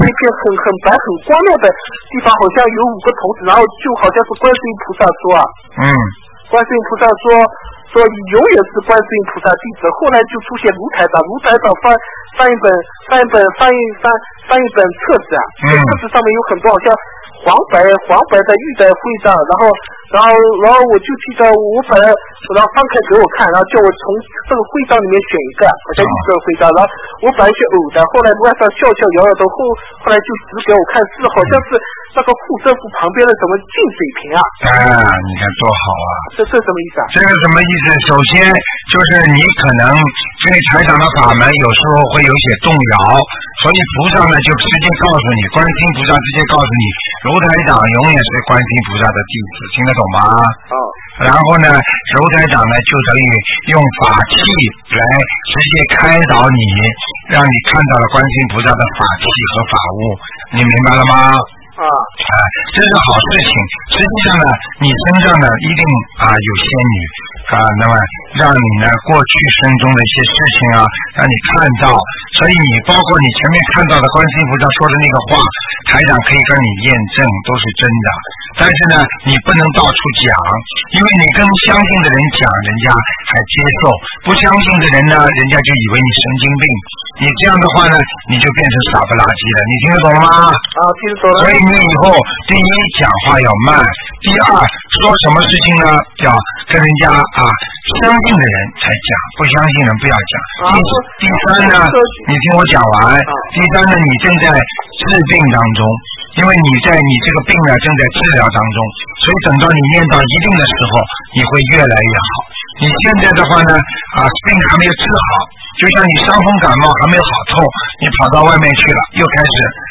电台录音精选